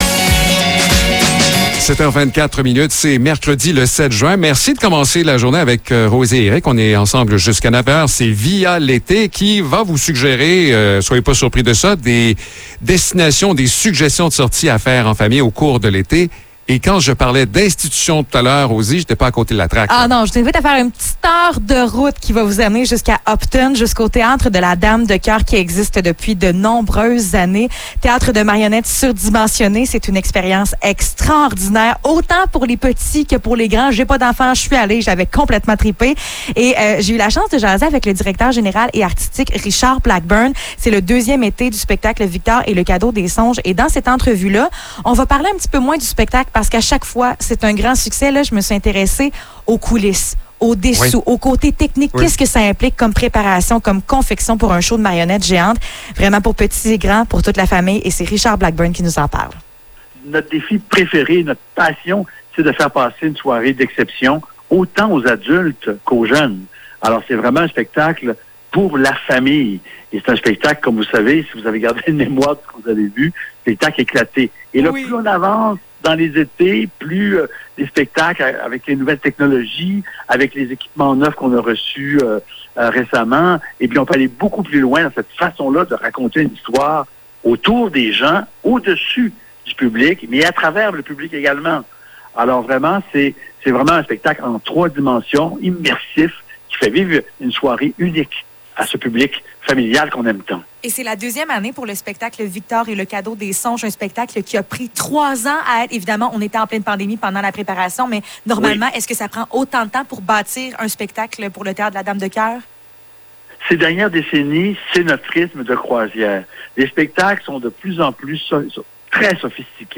Entrevue pour le Théâtre de la Dame de Coeur